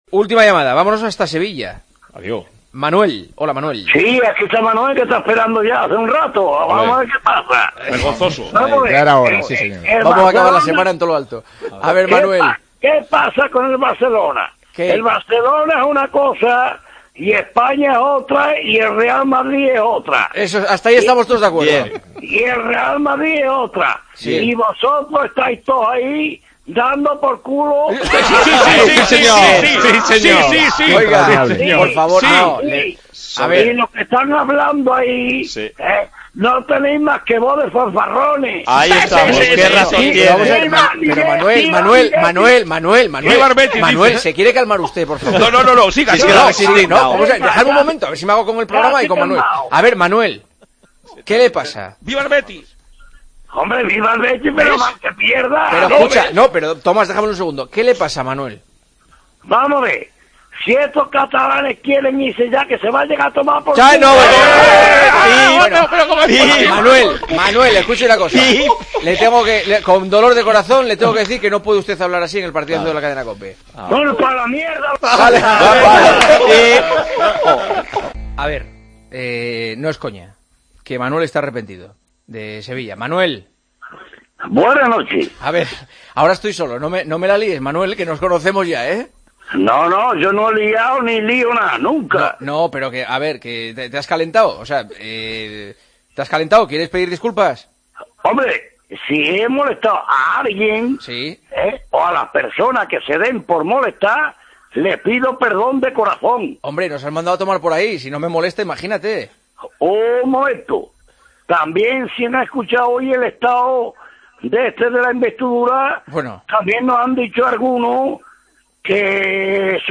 Una llamada para no perderse